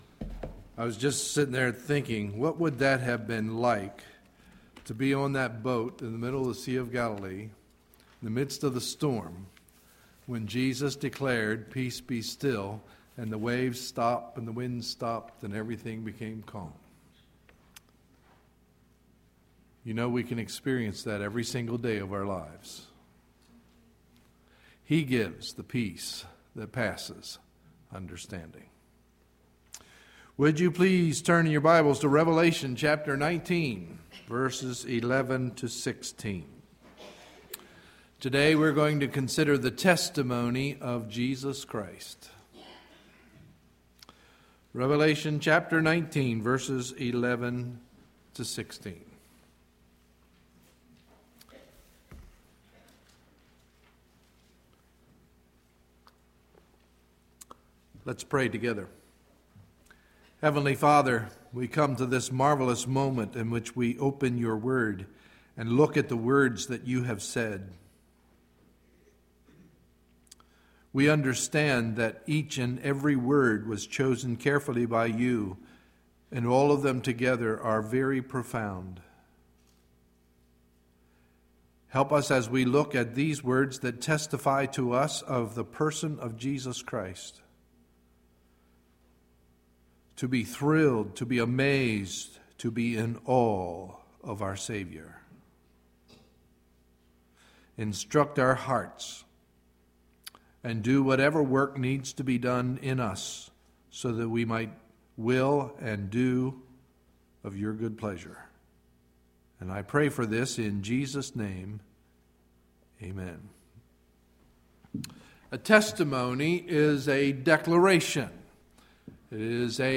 Sunday, November 20, 2011 – Morning Message